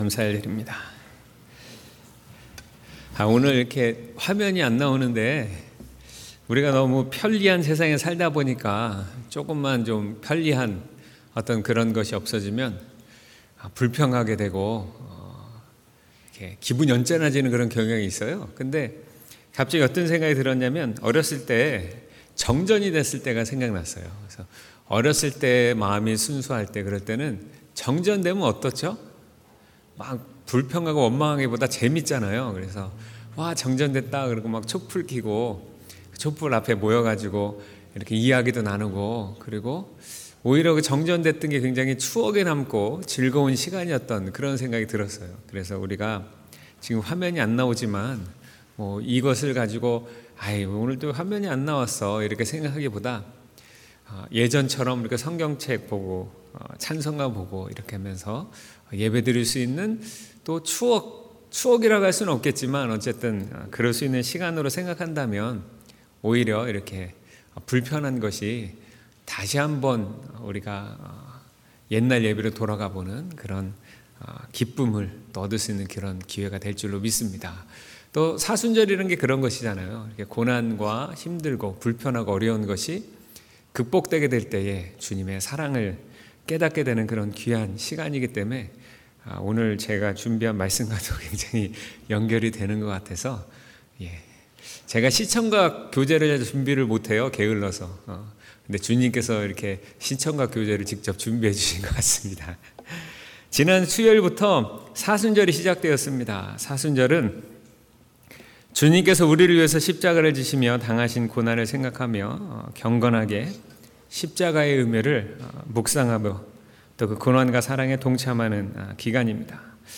3월 10일 주일 설교/사순절 설교 시리즈-십자가의 의미/마21: 9